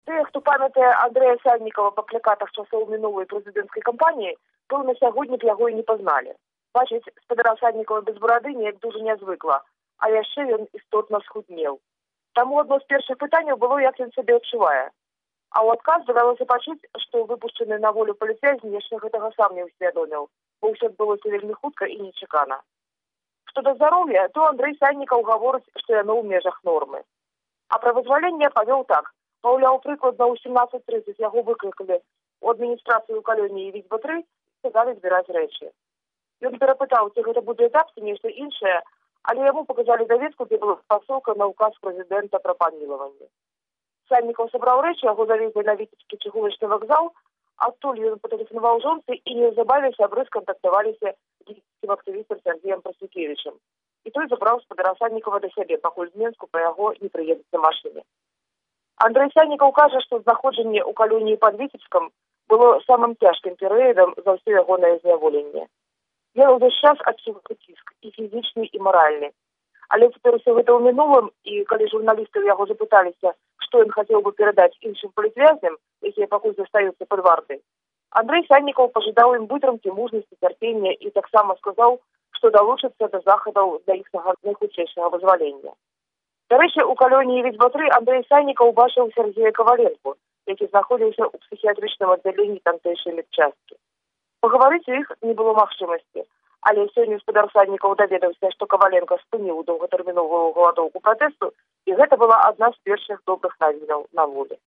Рэпартаж зь Віцебску